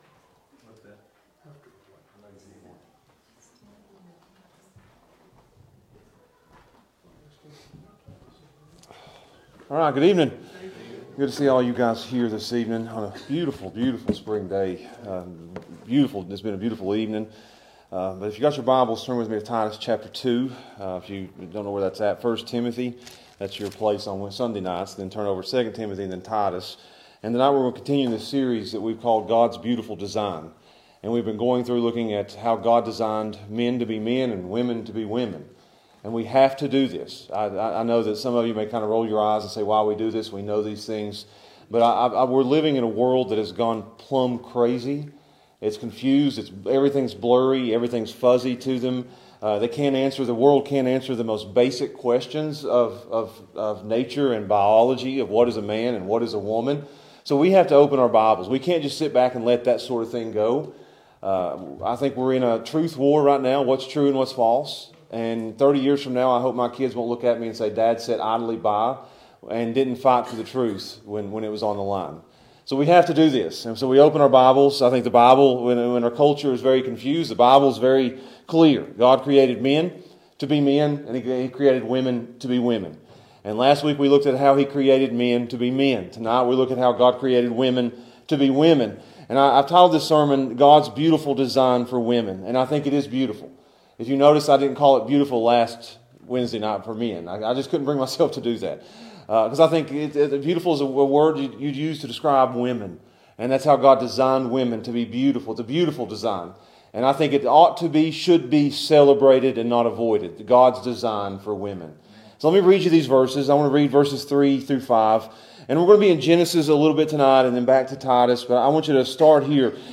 God's Design for Women | SermonAudio Broadcaster is Live View the Live Stream Share this sermon Disabled by adblocker Copy URL Copied!